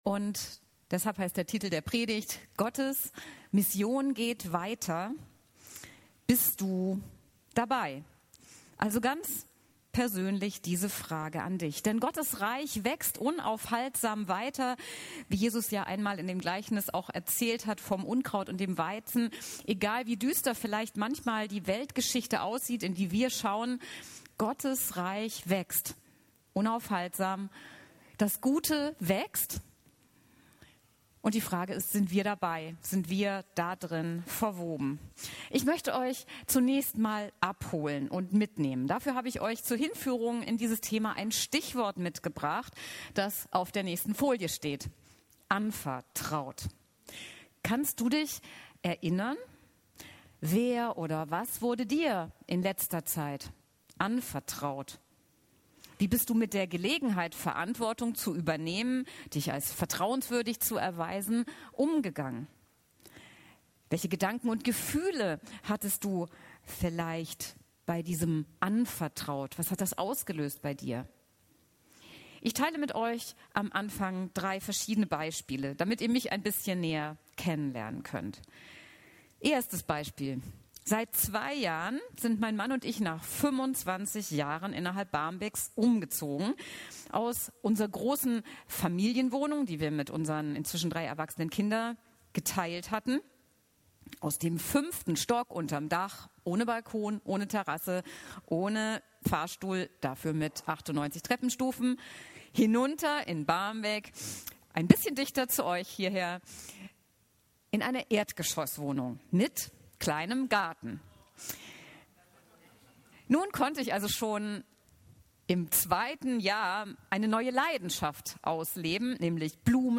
Eine motivierende Predigt im Rahmen der Allianzgebetswoche.